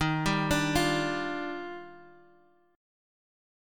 Listen to EbM9 strummed